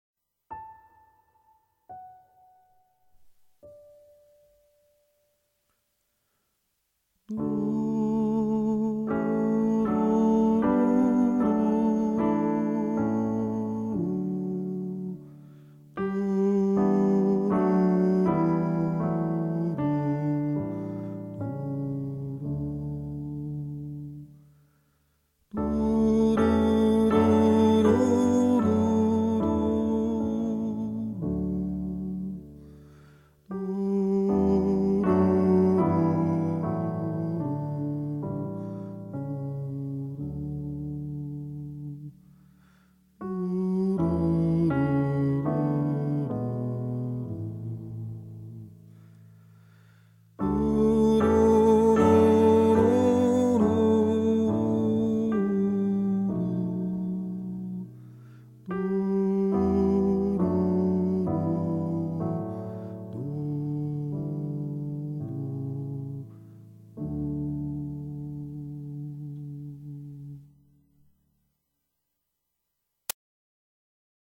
Neuriën